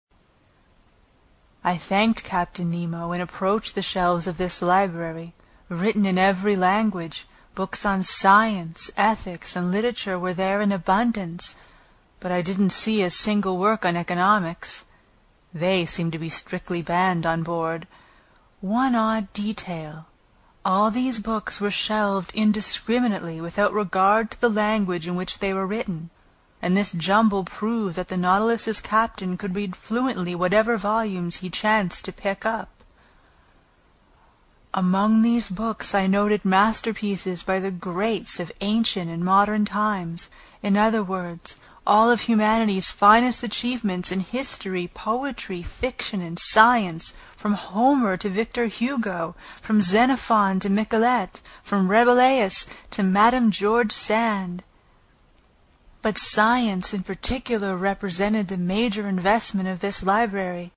英语听书《海底两万里》第152期 第11章 诺第留斯号(3) 听力文件下载—在线英语听力室
在线英语听力室英语听书《海底两万里》第152期 第11章 诺第留斯号(3)的听力文件下载,《海底两万里》中英双语有声读物附MP3下载